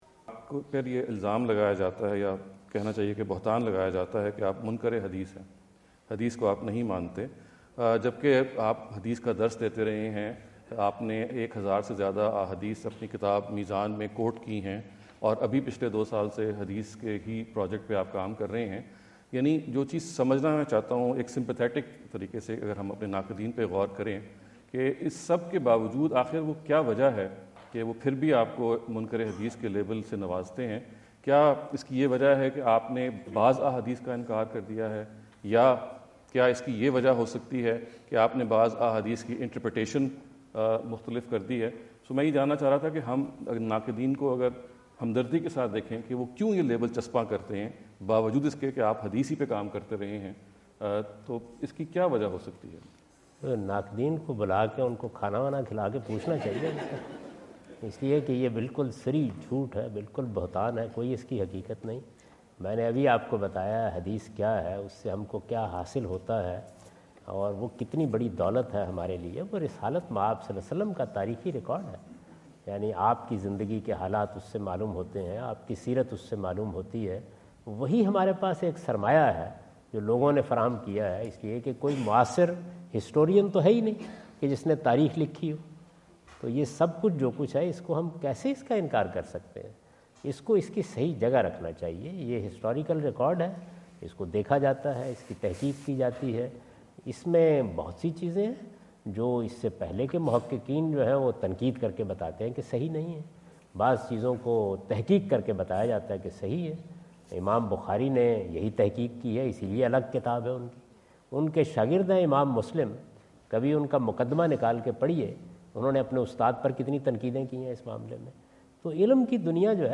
Javed Ahmad Ghamidi answer the question about "Why Critics Call Mr Ghamidi Munkar e Hadith (Rejecter of Hadith)?" asked at The University of Houston, Houston Texas on November 05,2017.